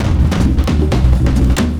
Index of /90_sSampleCDs/USB Soundscan vol.46 - 70_s Breakbeats [AKAI] 1CD/Partition A/27-133PERCS9